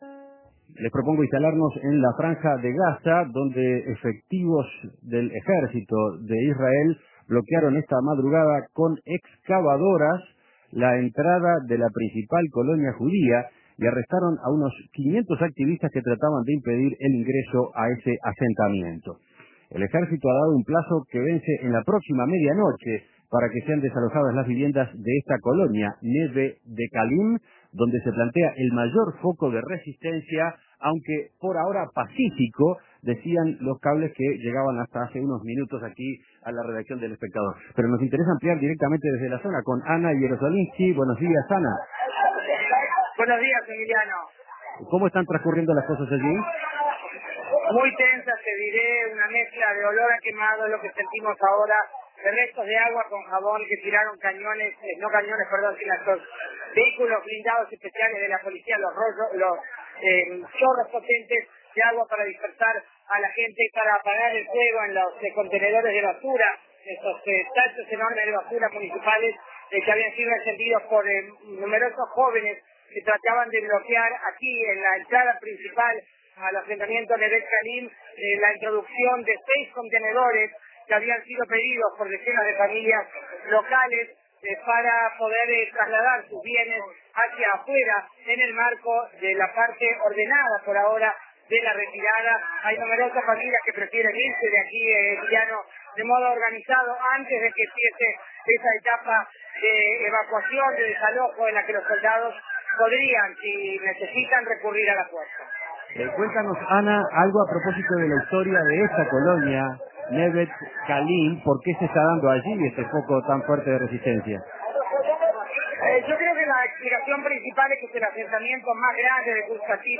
Reporte
desde uno de los asentamientos